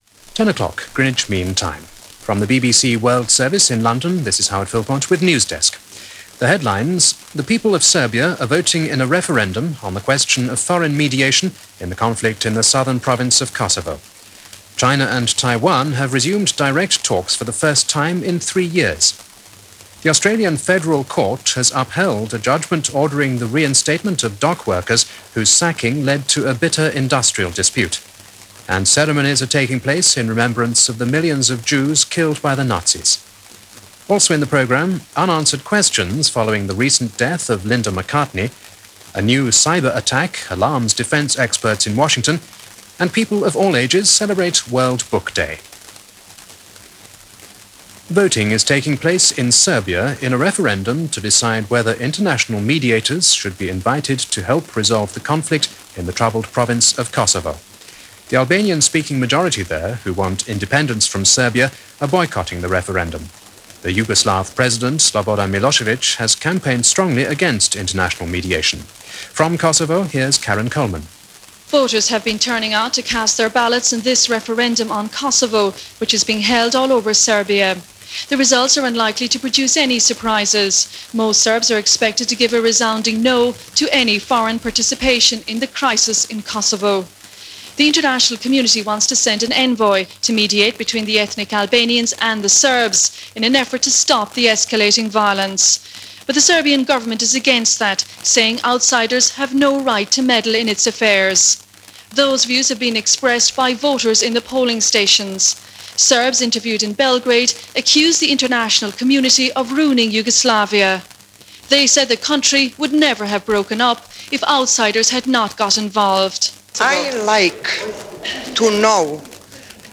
This day in 1998, as reported by The BBC World Service Newsdesk Program with focus on Bosnia and the ongoing war in the embattled region.